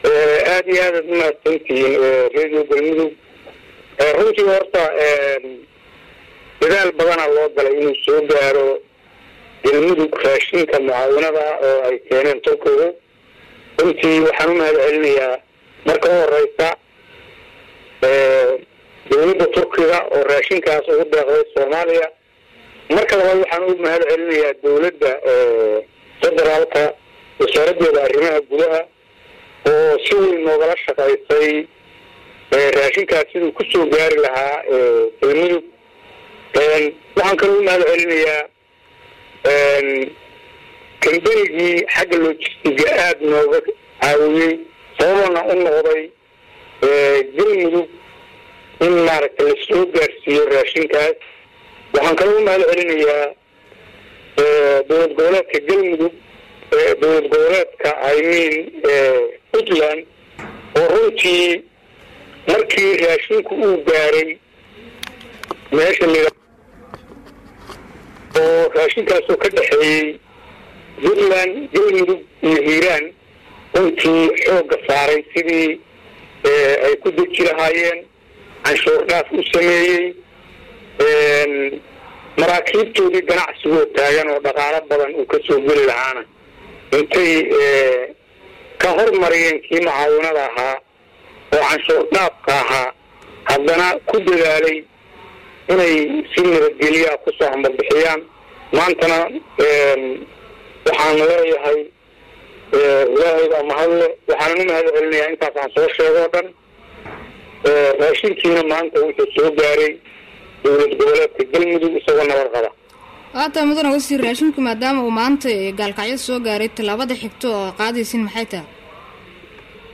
Dhagayso gudoomiyaha Xaafada Baraxleey